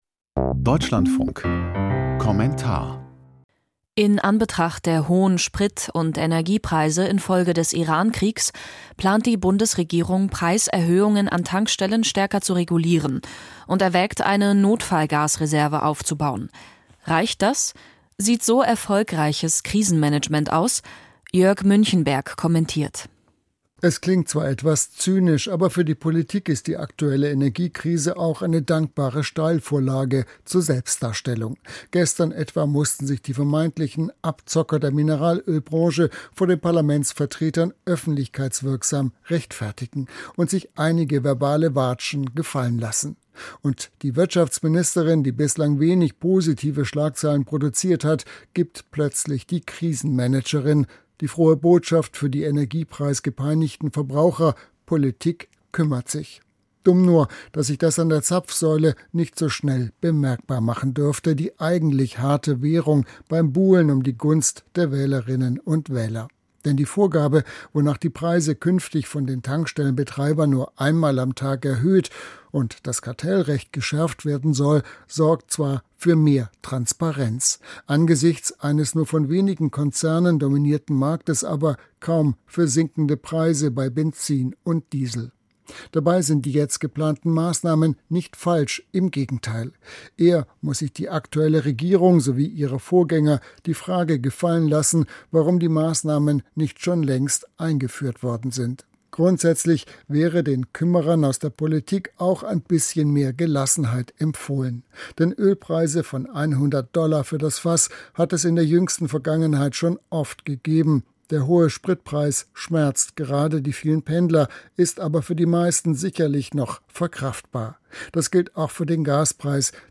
Kommentar zu hohen Energiepreisen: Erfolgreiches Krisenmanagement?